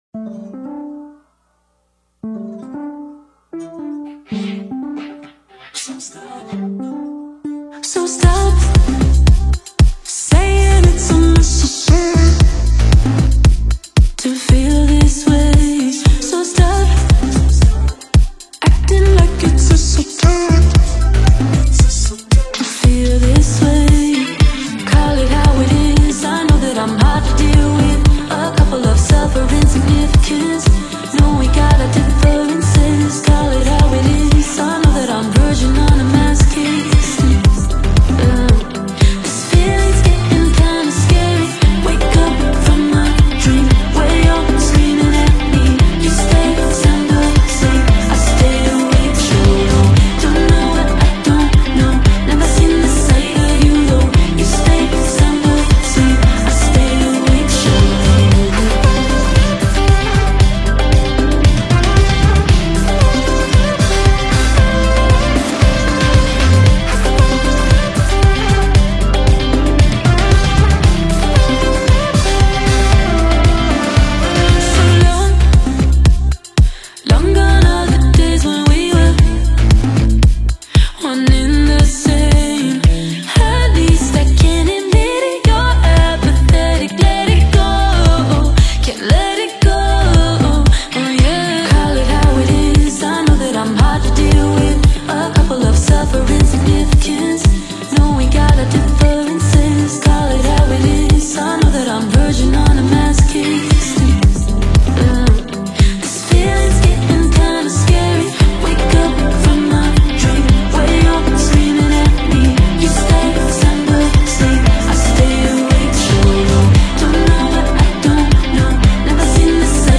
Hip Hop
alternative pop band
so catchy that you can’t help but fall in love with it